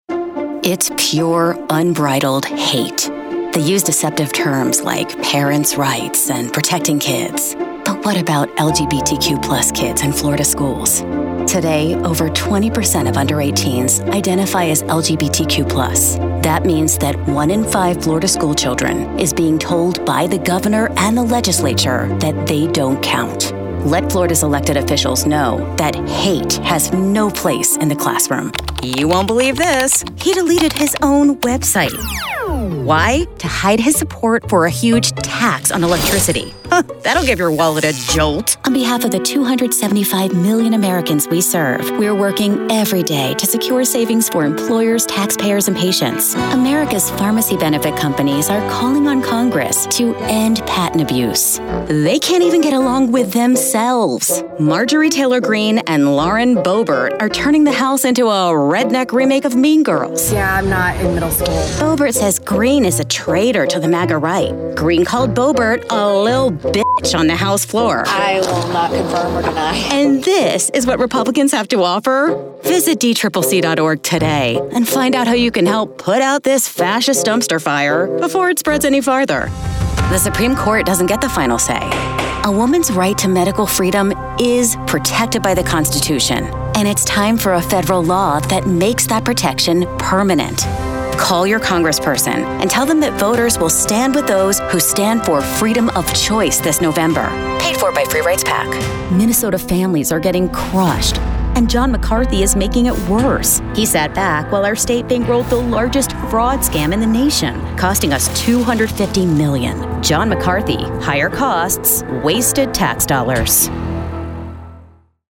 My voice description: warm, witty, and authentic.
Young Adult, Adult
Accents: standard us | natural